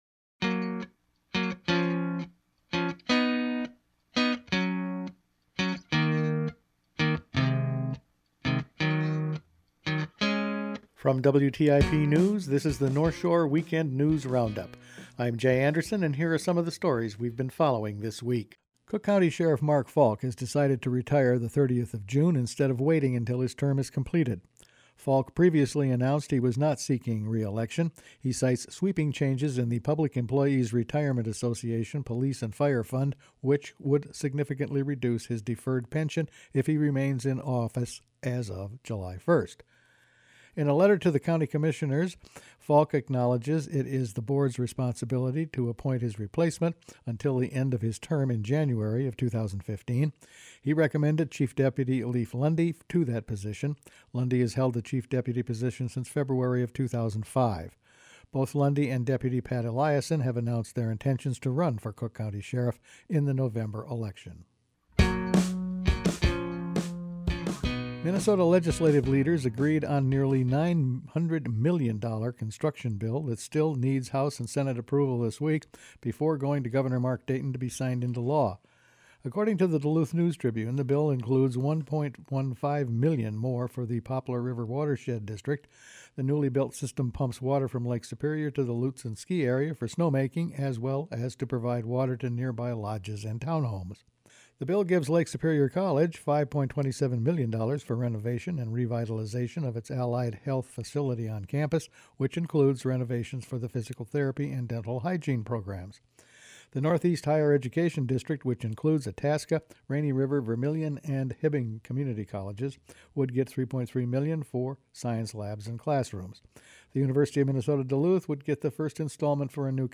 Each week the WTIP news team puts together a roundup of the week's news. It’s early retirement for the sheriff, Red Lake elects a new tribal chair, the Poplar River pipeline survives the bonding bill …all this and more in this week’s news.